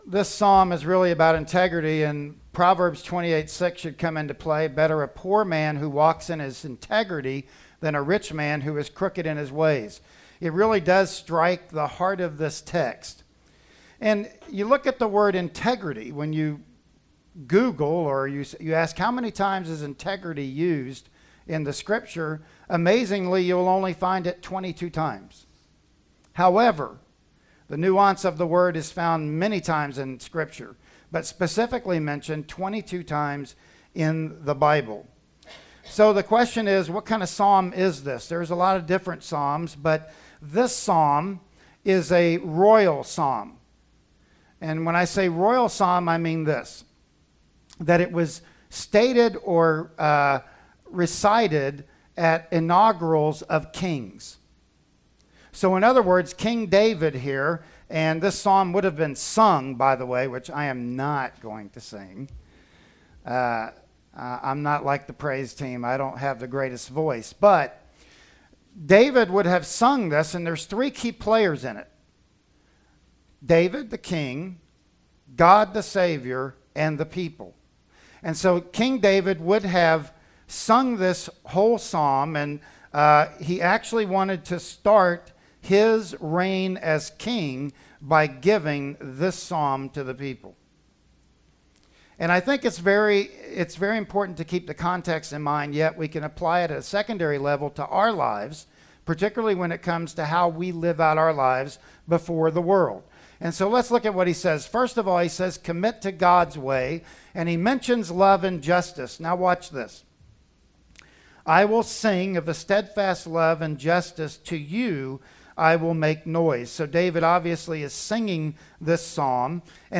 "Psalm 101:1-8" Service Type: Sunday Morning Worship Service « Missions